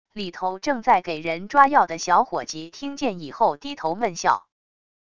里头正在给人抓药的小伙计听见以后低头闷笑wav音频生成系统WAV Audio Player